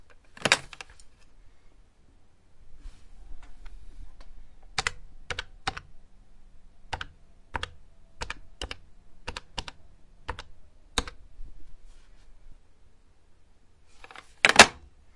声音效果 " 电话
描述：拿起电话听筒用按钮拨号，并将听筒放下。
Tag: 个按键 Putdown电话的手机 接听电话的手机